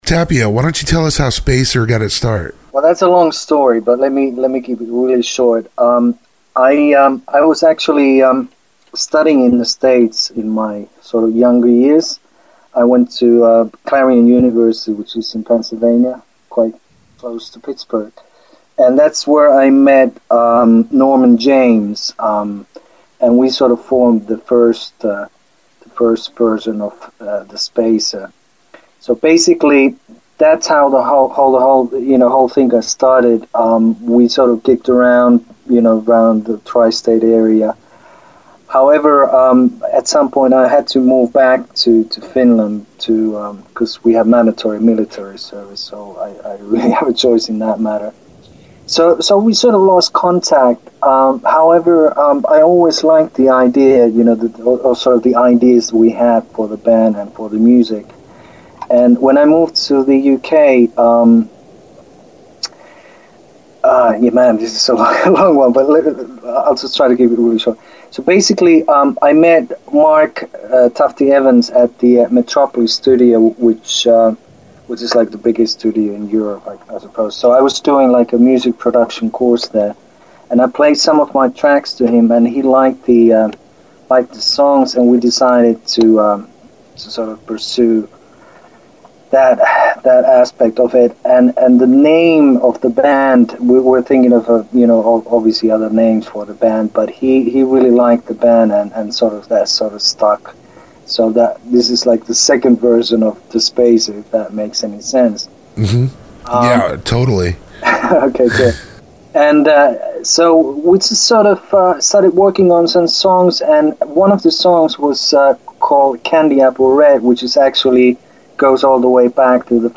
An Interview
thespacerinterview.mp3